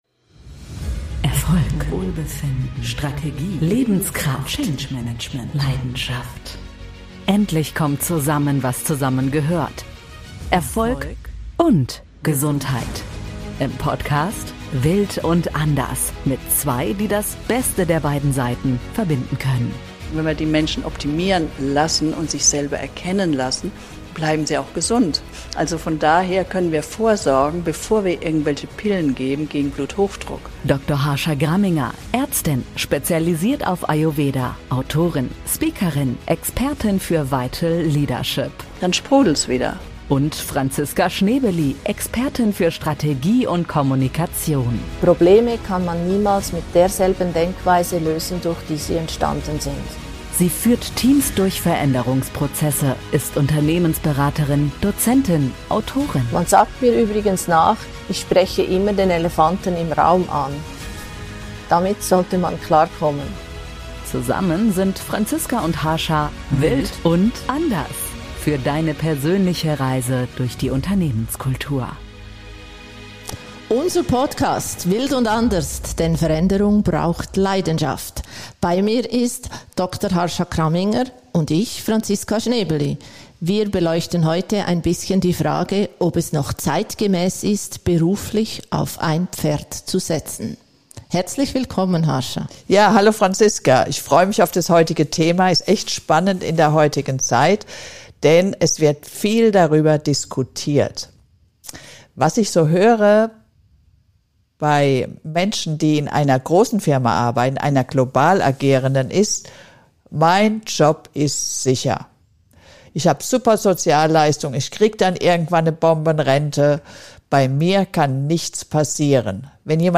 Müssen wir uns breiter aufstellen in Zukunft? Die beiden Expertinnen tauschen sich in der Folge über dieses spannende Thema aus.